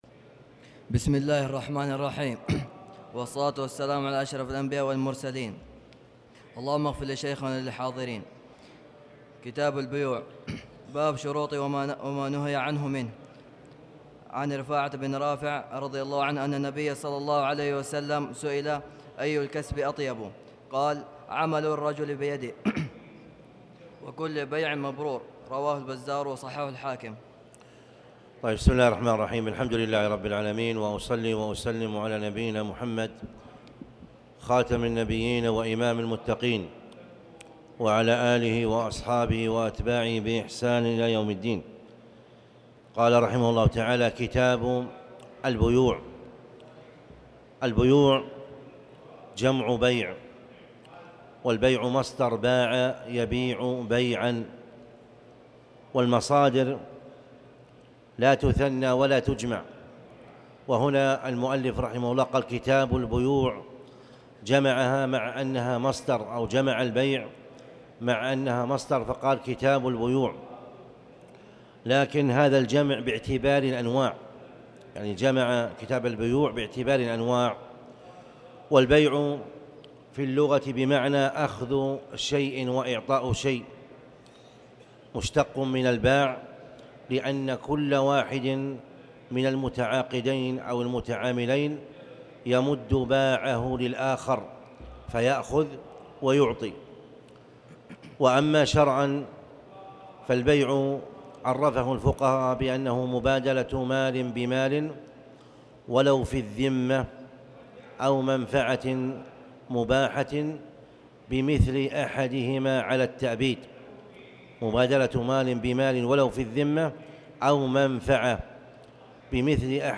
تاريخ النشر ٢٣ محرم ١٤٤٠ هـ المكان: المسجد الحرام الشيخ